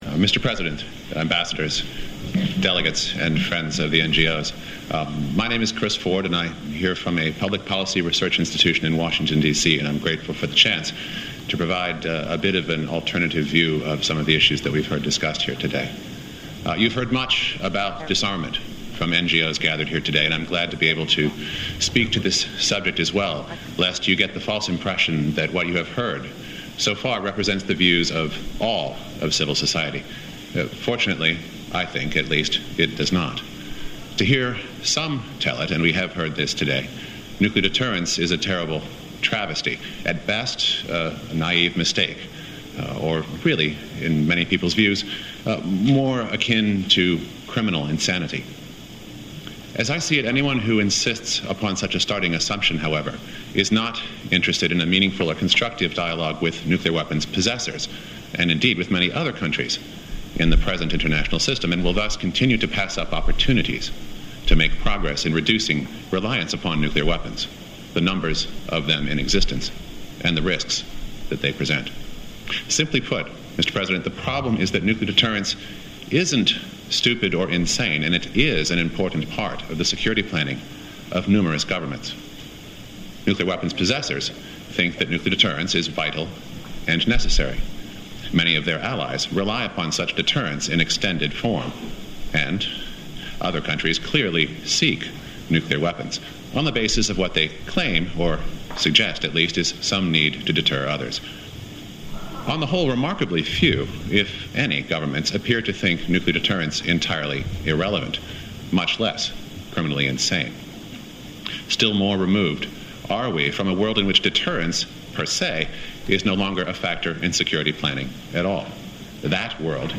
Christopher Ford, Hudson Institute at the NGO Presentations to the NPT